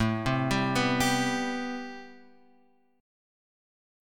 A Minor 9th